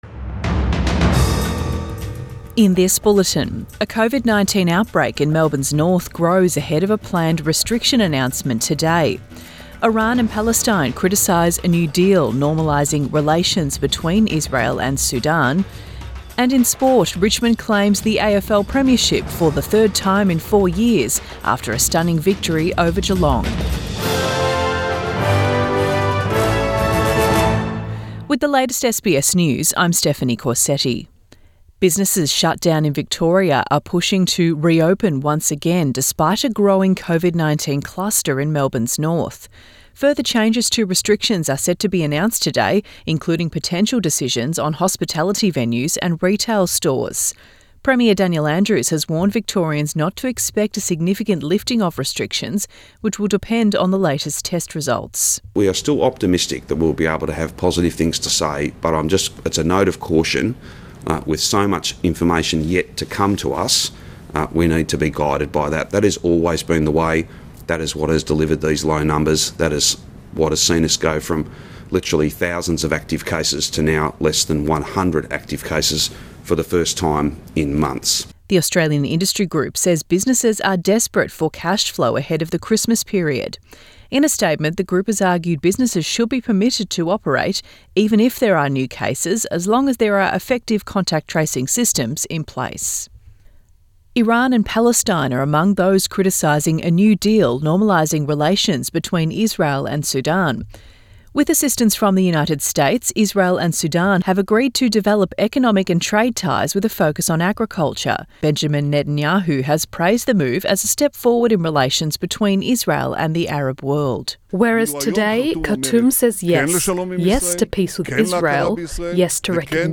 AM bulletin 25 October 2020